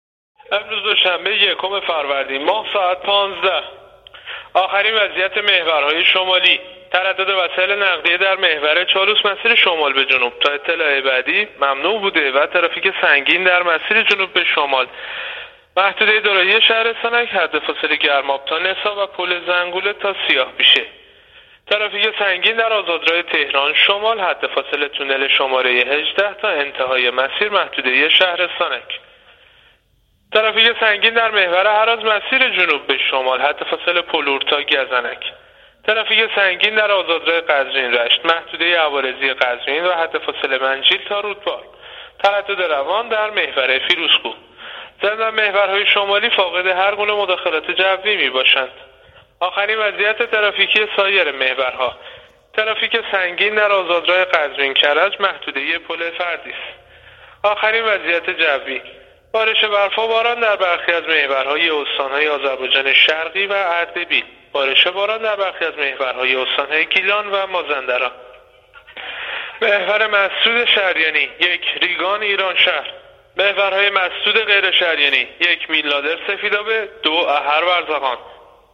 گزارش رادیو اینترنتی از آخرین وضعیت ترافیکی جاده‌ها تا ساعت ۱۵ یکم فروردین ماه؛